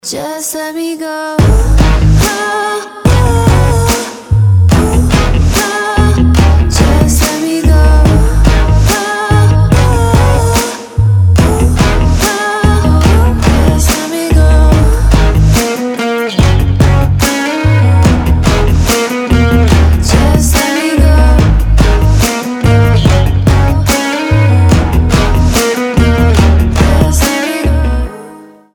• Качество: 320, Stereo
женский вокал
Electronic
электрогитара
кайфовые
Chill Trap
alternative